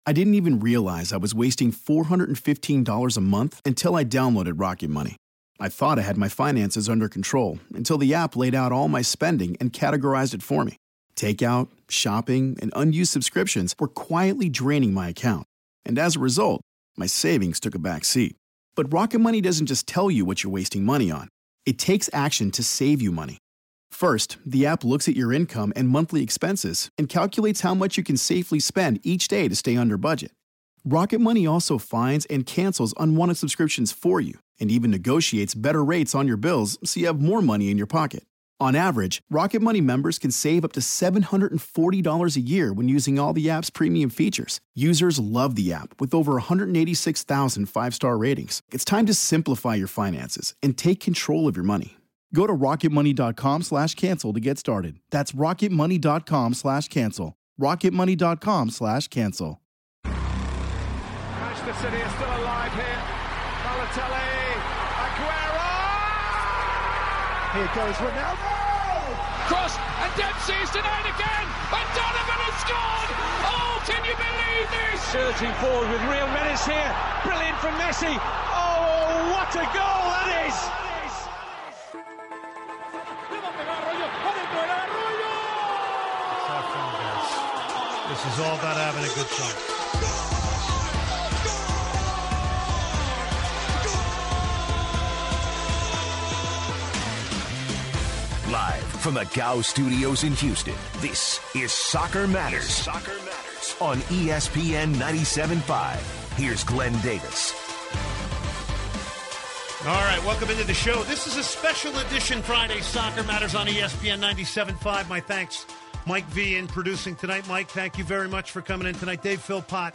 Today's edition of soccer matters features two special guests!